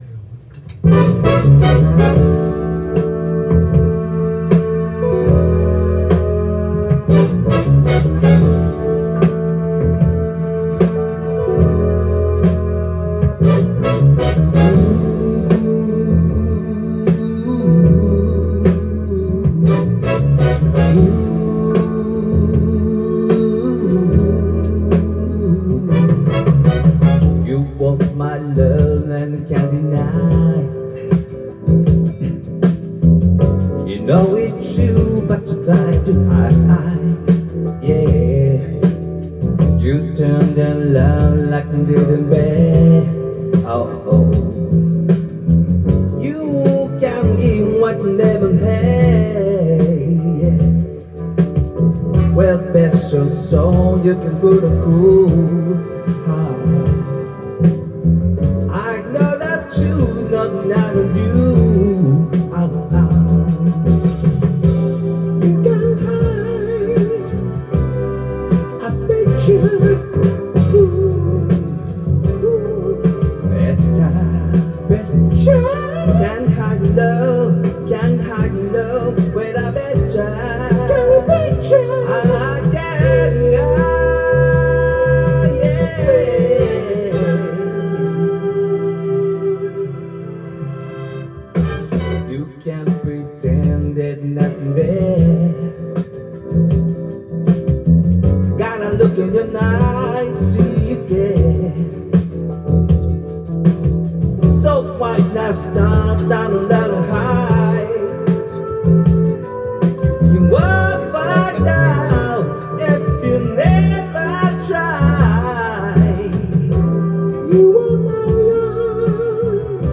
Duet & Chorus Night Vol. 22 TURN TABLE